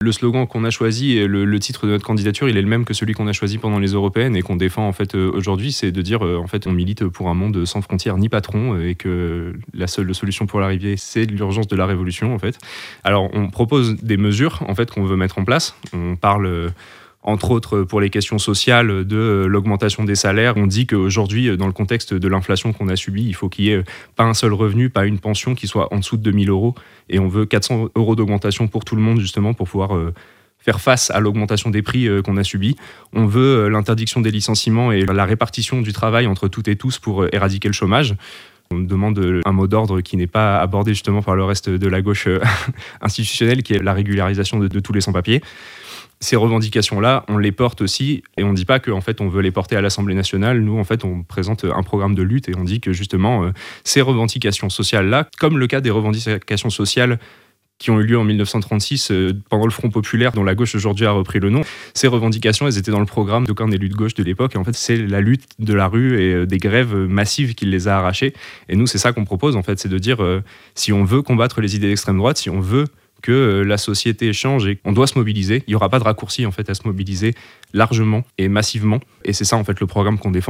Voic les interviews des 8 candidats de cette 2eme circonscription de Haute-Savoie (par ordre du tirage officiel de la Préfecture) :